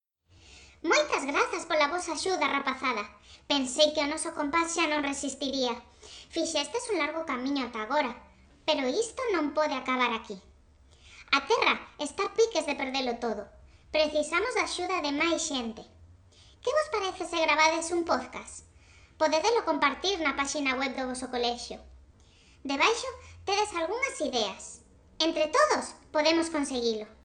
Lectura facilitada